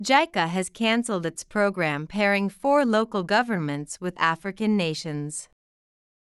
１文ずつ区切ったスロー音声を再生し、文字を見ずにリピートしましょう。